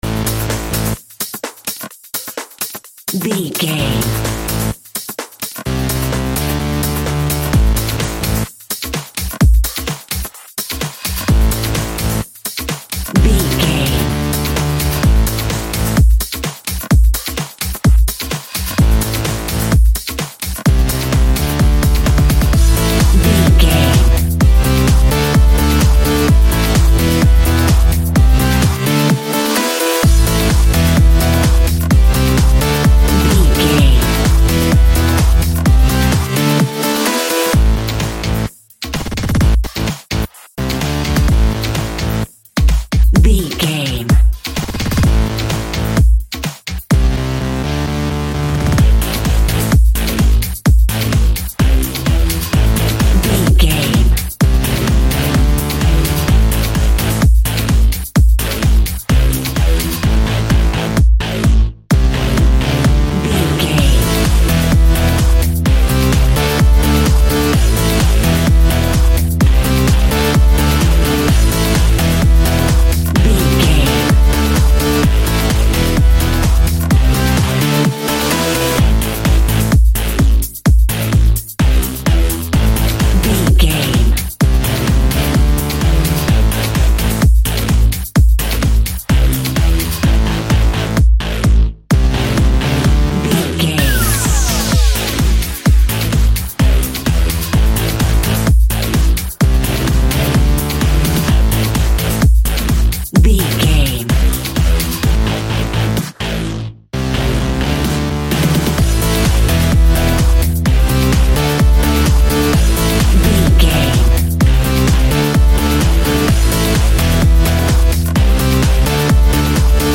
Aeolian/Minor
Fast
groovy
driving
energetic
hypnotic
industrial
heavy
drum machine
synthesiser
house
electro dance
techno
trance
synth leads
synth bass
upbeat